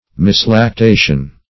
Search Result for " mislactation" : The Collaborative International Dictionary of English v.0.48: Mislactation \Mis`lac*ta"tion\, n. (Med.)